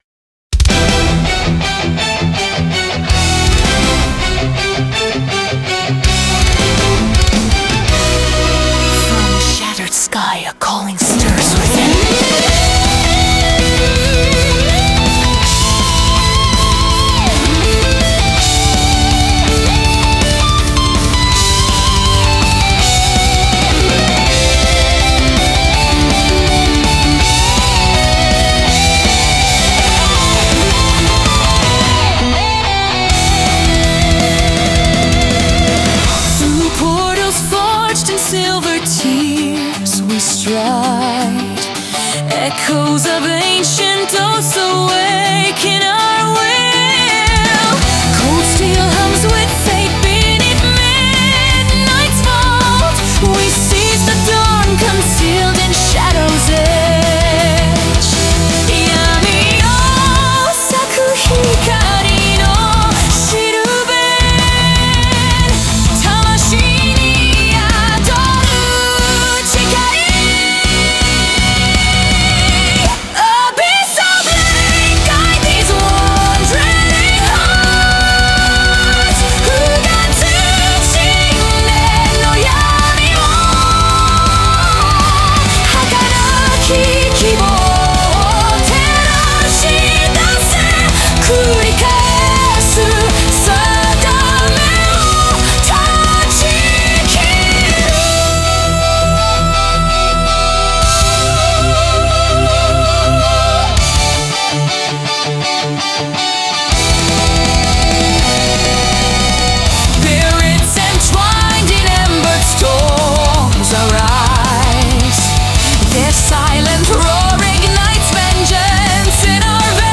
Symphonic Metal